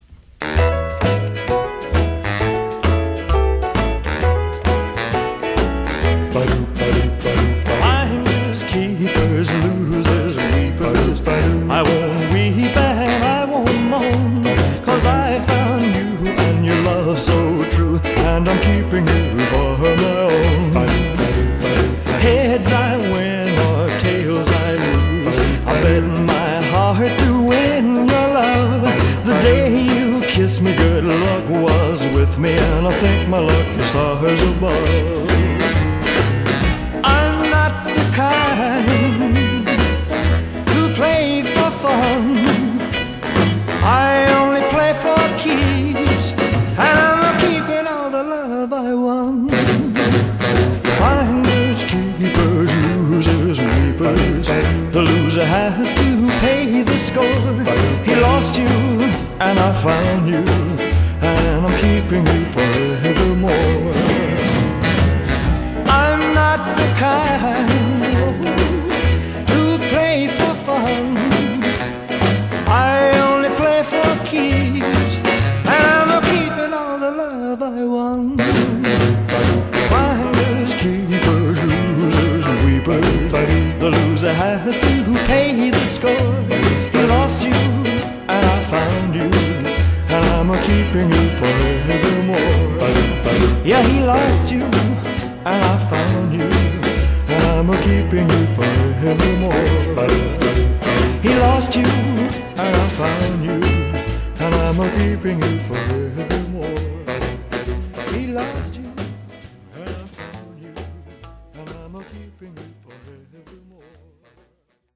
Music Rock Doo-wop pop